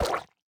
ink_sac1.ogg